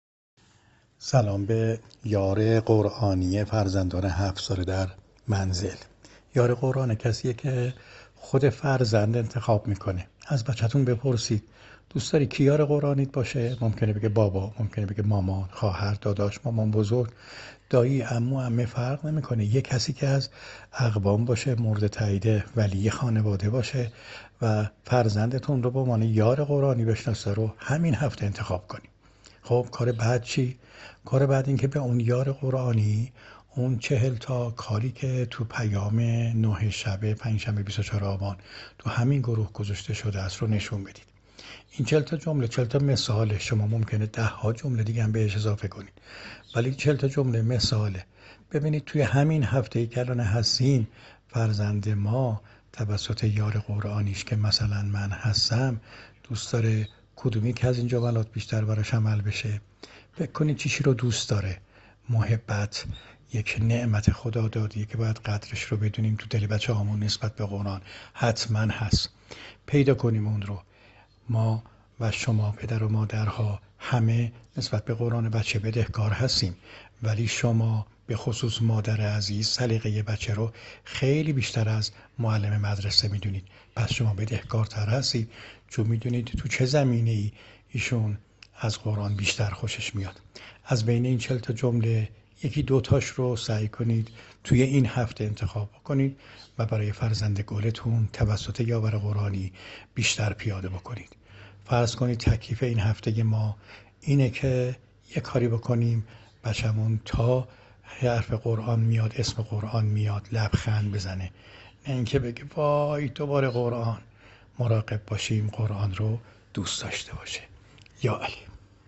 پاسخ صوتی